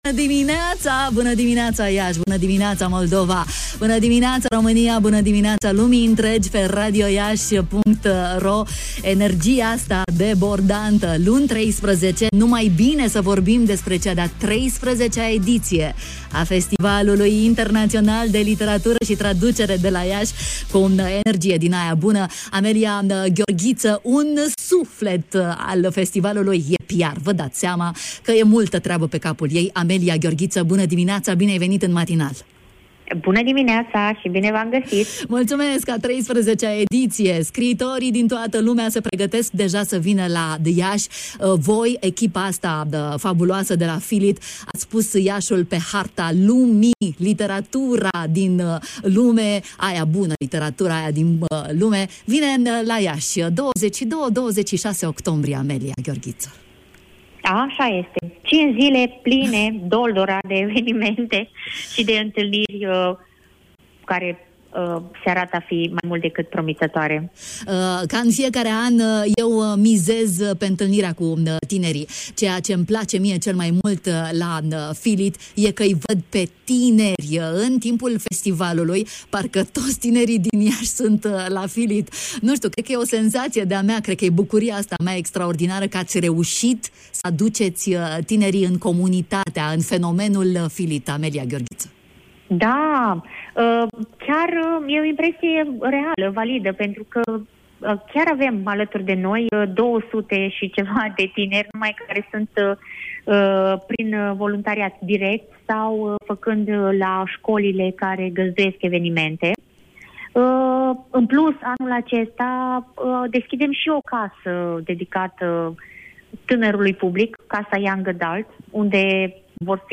Cu detalii în matinalul de la Radio România Iași